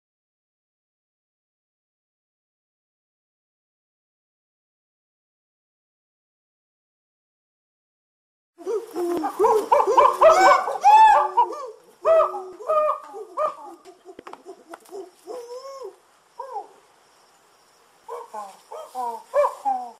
Monkey Noises To Annoy Ur Teacher